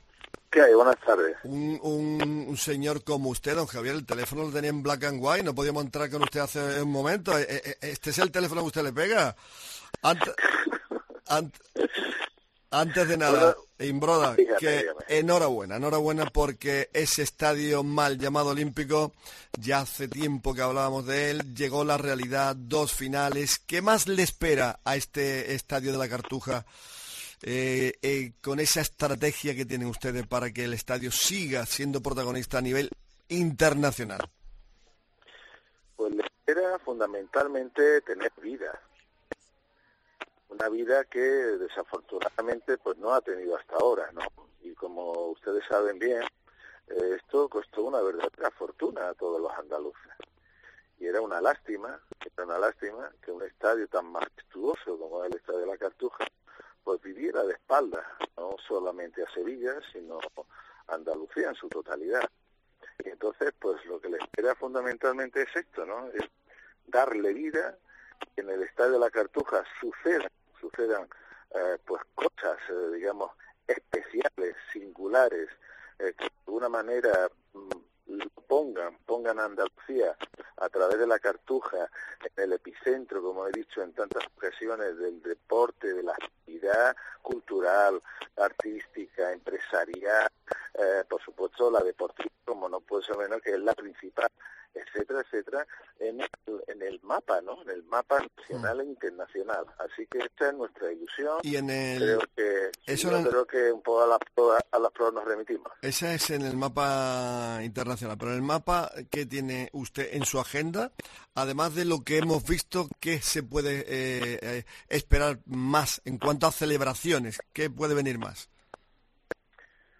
LA ENTREVISTA DE JAVIER IMBRODA EN DEPORTES COPE SEVILLA